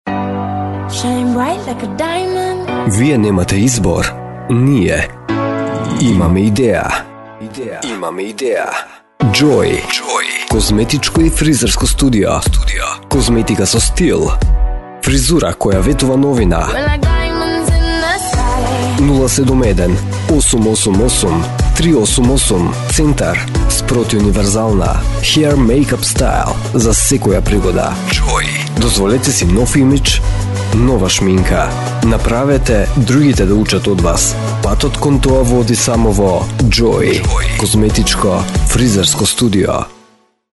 Deep Voice, Serious, Radio Sweepers, Jingles
Sprechprobe: Industrie (Muttersprache):